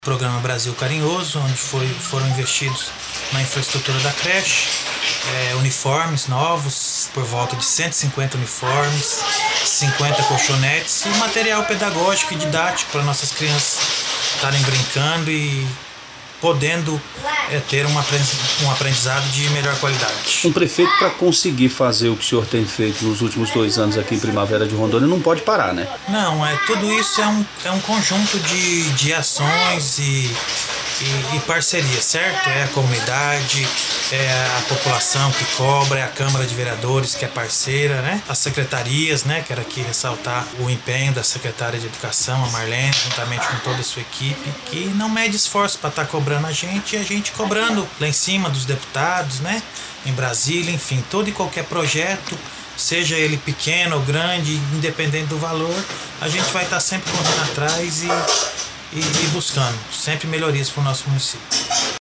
Ouça as declarações do prefeito.